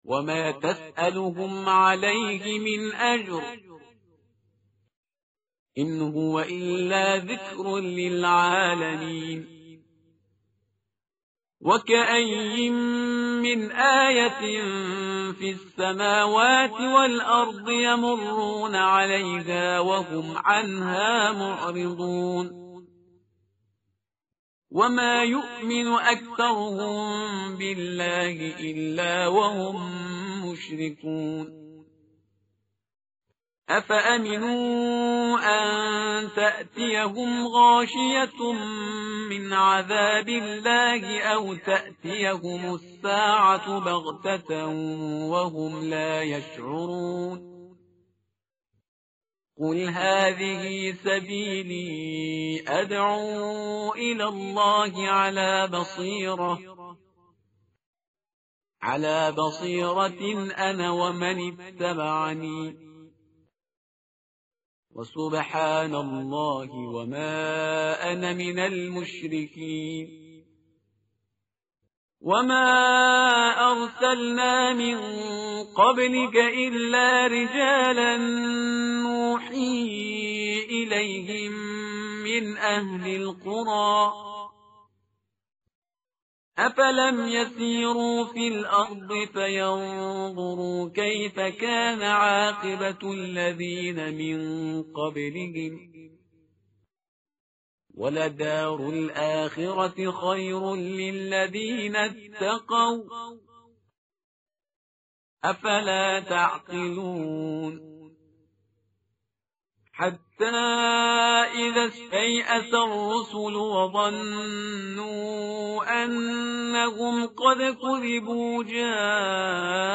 tartil_parhizgar_page_248.mp3